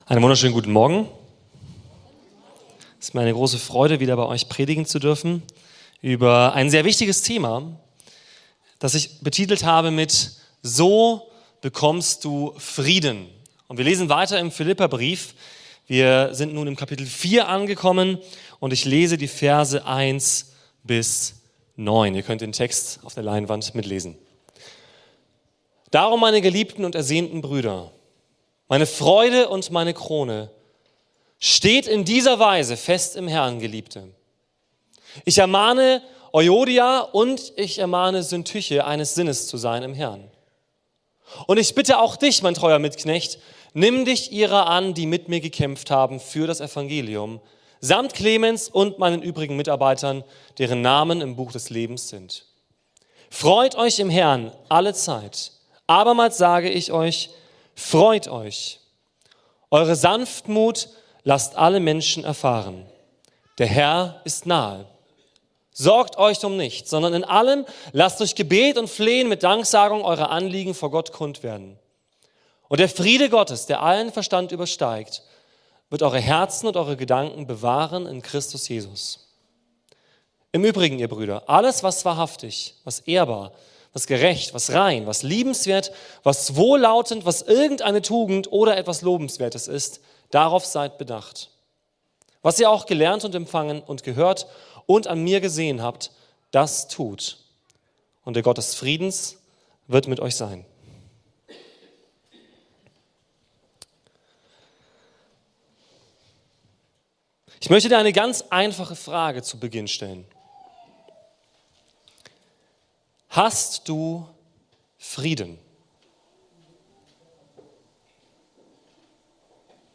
Predigten